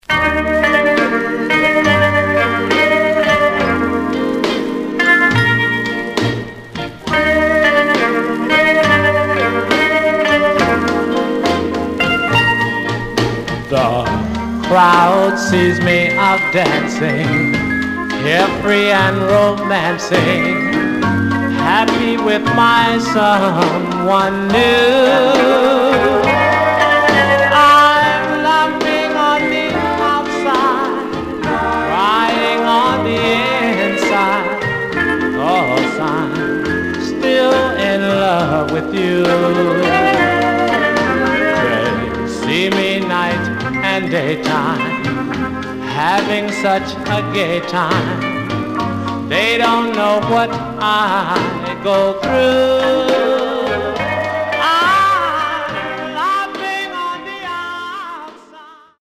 Some surface noise/wear Stereo/mono Mono
Male Black Groups